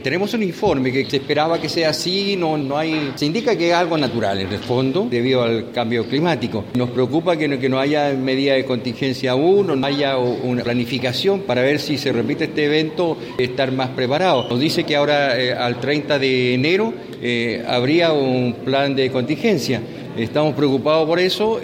El reelecto Alcalde de Calbuco, Rubén Cárdenas, se refirió al tema de la marea roja y de la forma que se ha abordado el problema haciendo presente que ya conocido el informe, aun no hay plan de contingencia para enfrentar otra situación de similares características en la zona.